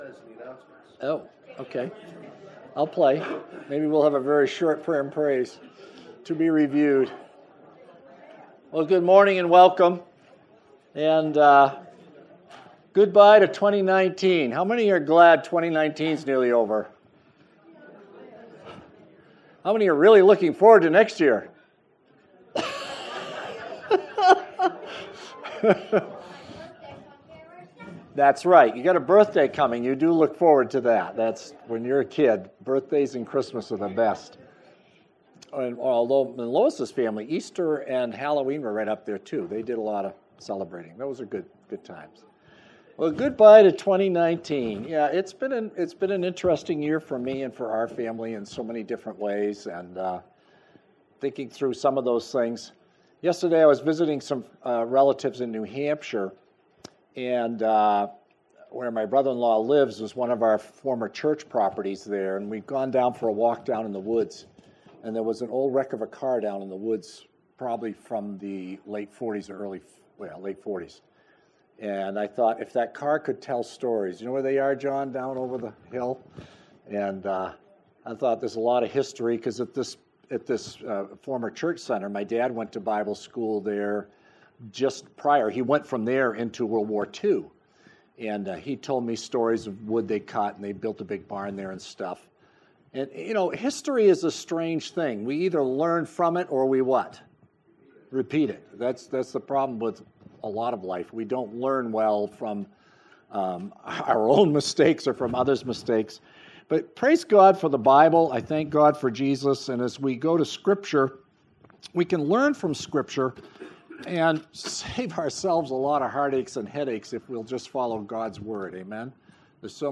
Year-End-Sermon-2019.mp3